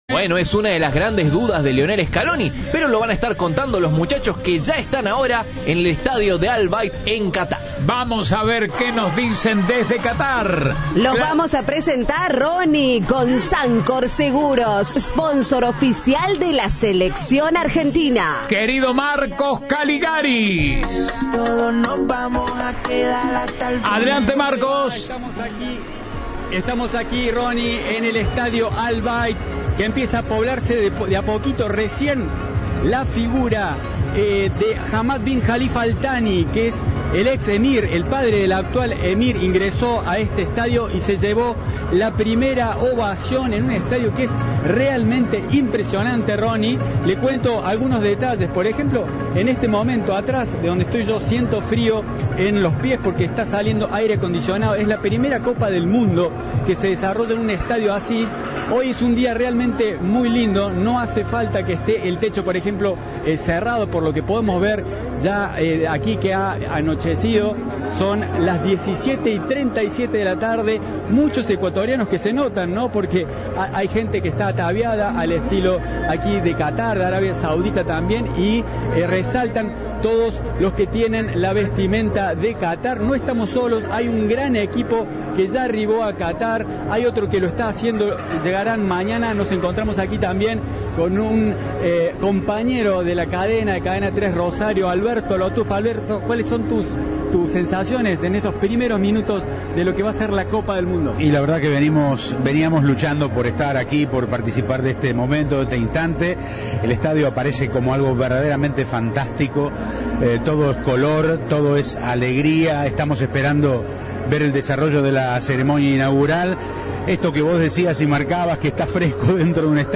Audio. Cadena 3 presente en la apertura de la Copa del Mundo desde el Al Bayt Stadium